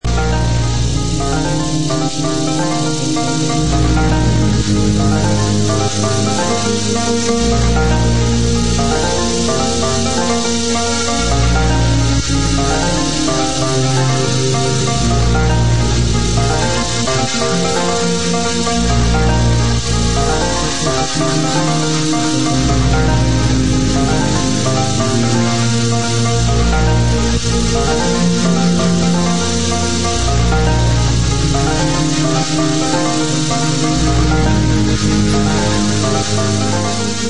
London dance music
Techno
Bass